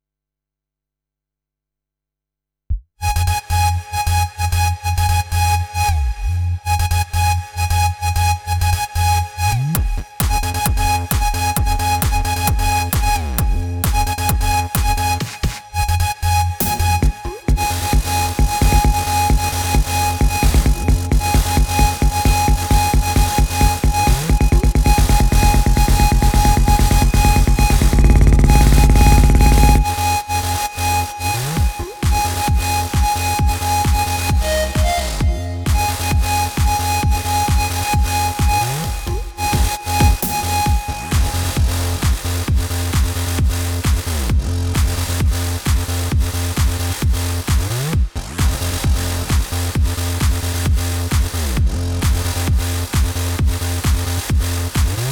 הייתי אומר להוריד טיפה את התווים או איך שלא קוראים לזה לתווים אחרים, נמוכים יותר, זה נשמע צעקני מדי חוץ מזה הכל מושלם!!!